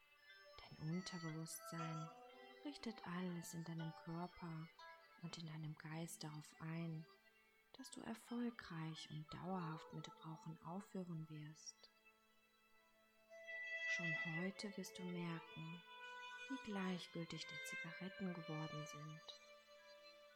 Suggestionsdusche: Hypnotische Rauchentwöhnung
Genießen Sie eine wunderbare Entspannung und nehmen Sie sich eine Atempause mit unserer MP3-Suggestionsdusche mit angenehmer Entspannungsmusik.
R1002-Suggestionsdusche-Rauchentwoehnung-Hoerprobe.mp3